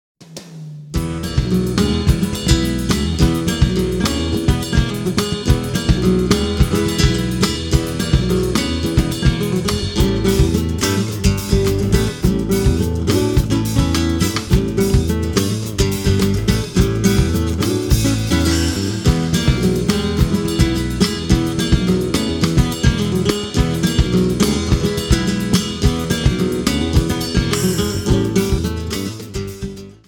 A play-along track in the style of Brazilian.